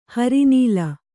♪ hari nīla